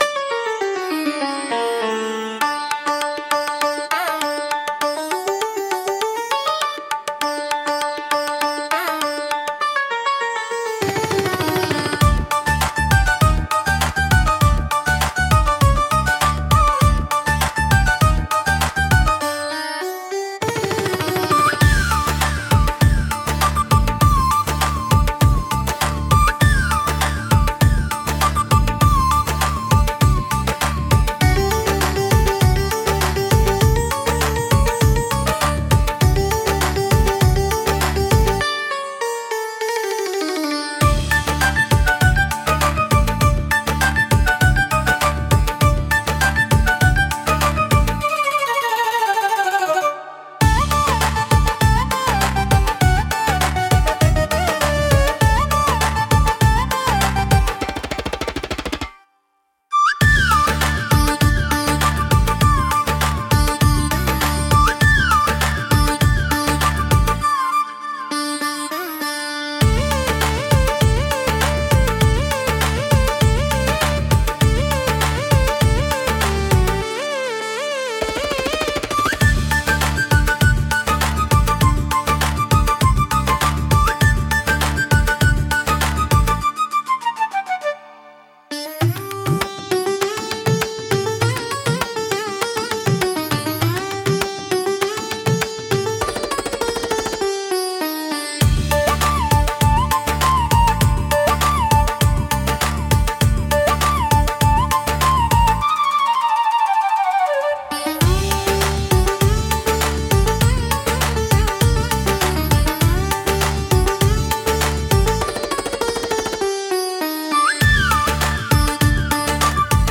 聴く人に心の安らぎと独特の世界観を届ける、奥行きのあるジャンルです。